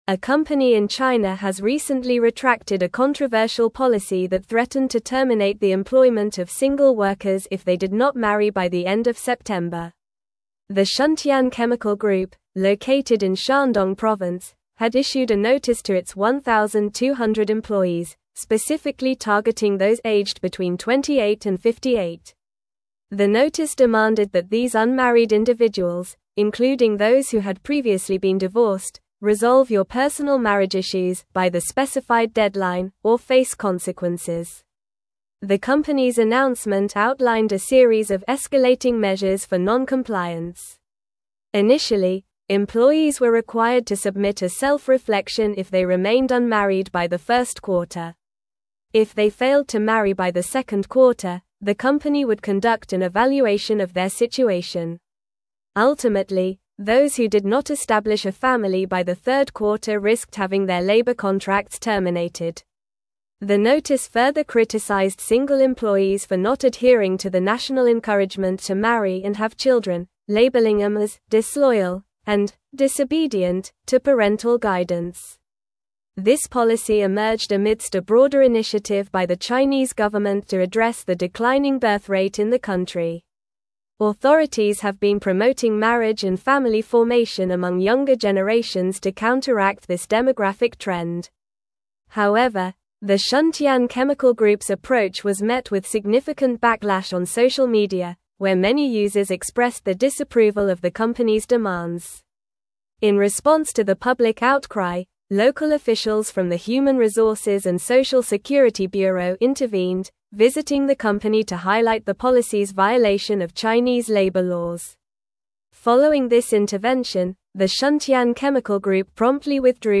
Normal
English-Newsroom-Advanced-NORMAL-Reading-Company-Faces-Backlash-Over-Controversial-Marriage-Policy.mp3